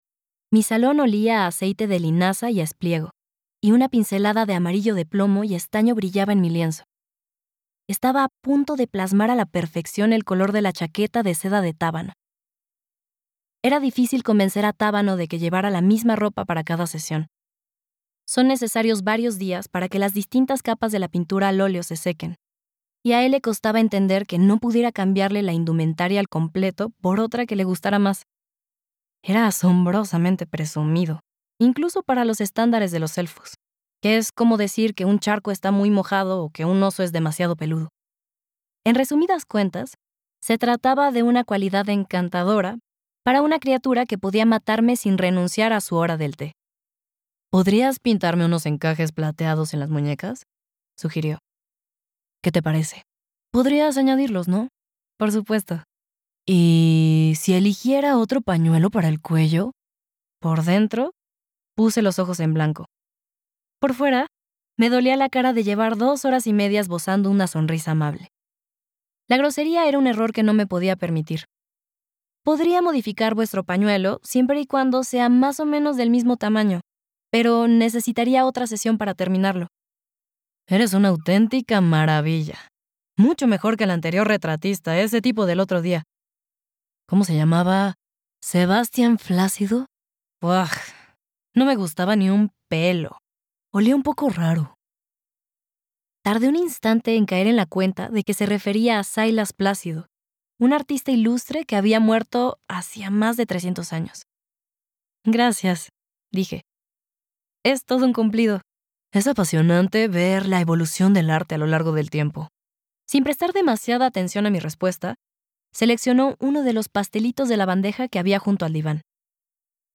Audiolibro Un encantamiento de cuervos (An Enchantment of Ravens)